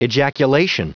Prononciation du mot ejaculation en anglais (fichier audio)
ejaculation.wav